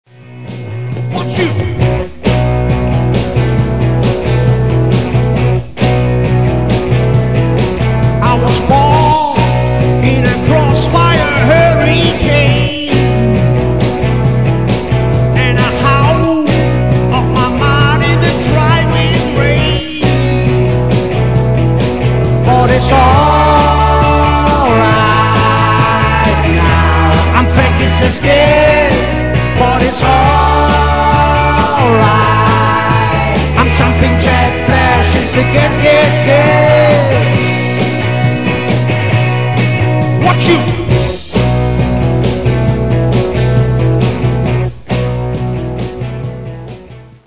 Das Pop/Rock-Musik Programm